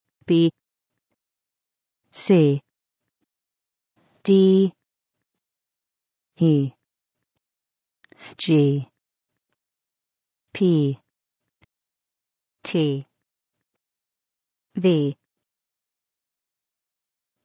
Note: il s'agit ici des prononciations anglaises.